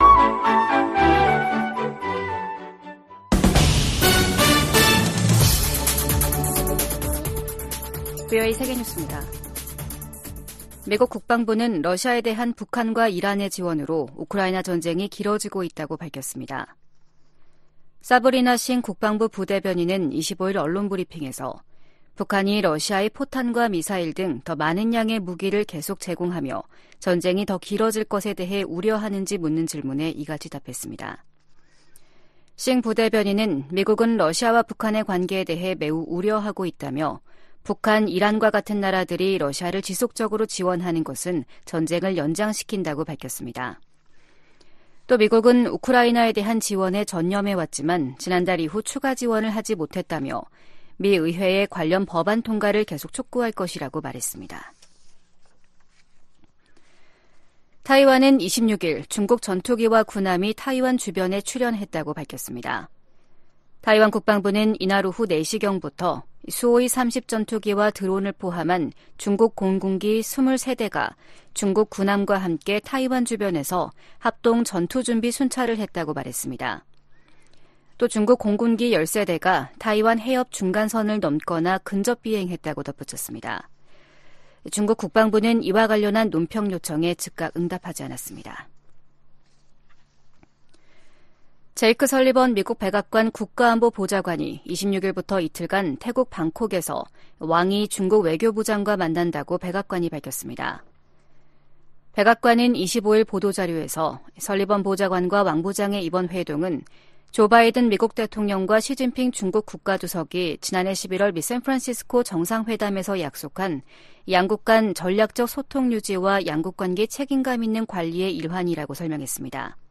VOA 한국어 아침 뉴스 프로그램 '워싱턴 뉴스 광장' 2024년 1월 27일 방송입니다. 제네바 군축회의에서 미국과 한국 등이 북한의 대러시아 무기 지원을 규탄했습니다. 미 국방부는 북한의 대러시아 무기 지원이 우크라이나 침략 전쟁을 장기화한다고 비판했습니다. 김정은 북한 국무위원장은 지방 민생이 생필품 조차 구하기 어려운 수준이라면서, 심각한 정치적 문제라고 간부들을 질타했습니다.